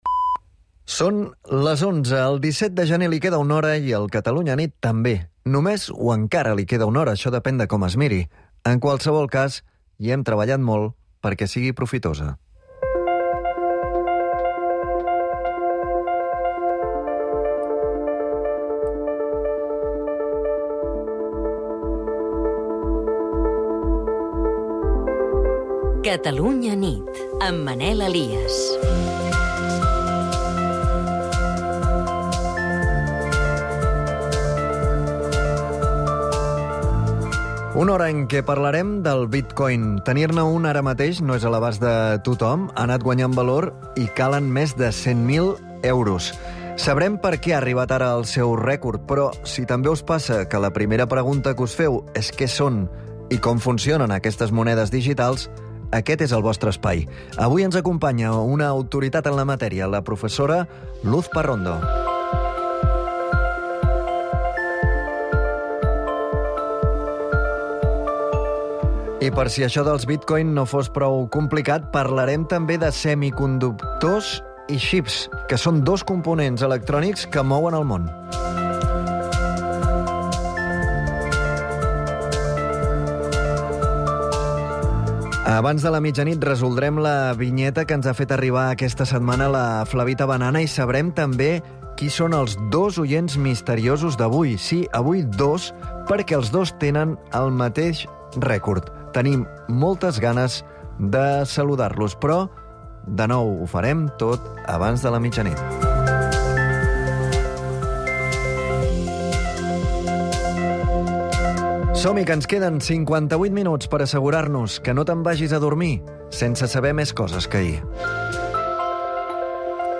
informatiu diari